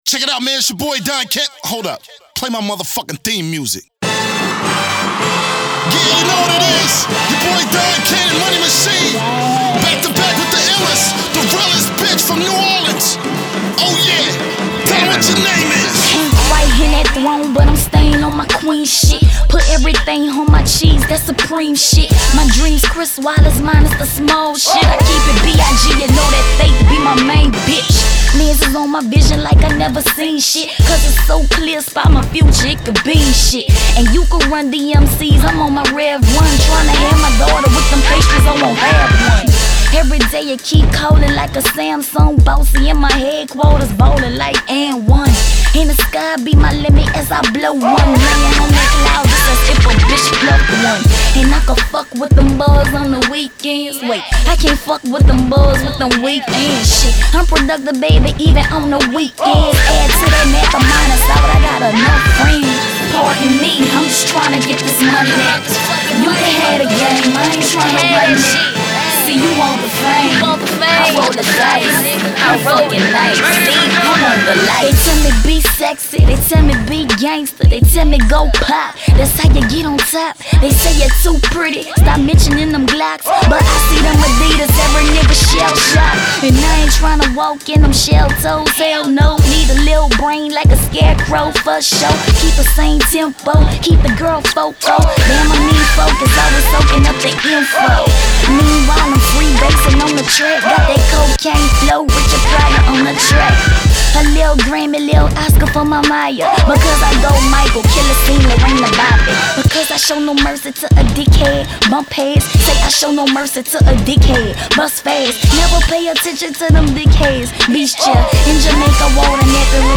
Genre: Southern Rap.